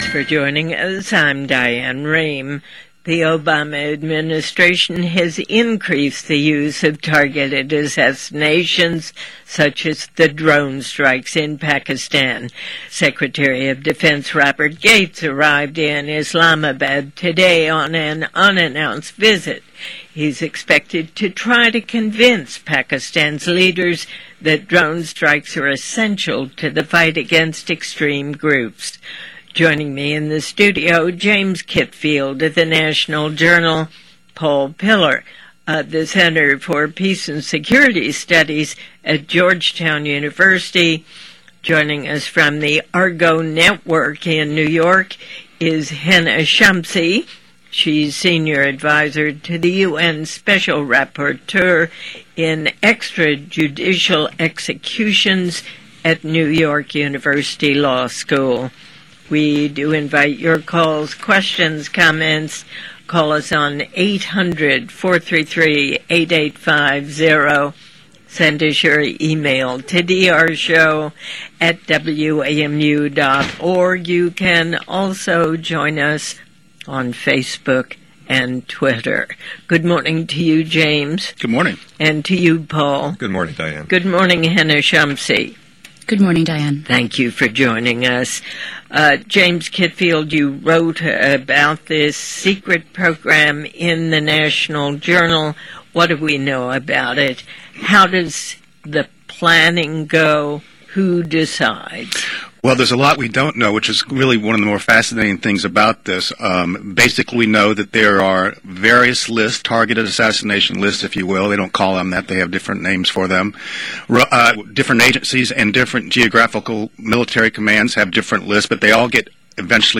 Diane Rehms discusses the implications of targeted assassinations in the war against al Qaeda on WAMU 88.5 FM. The Obama administration has accelerated the use of drones for targeted assassinations during the past year, and she details the moral and legal issues surrounding the use of lethal covert action.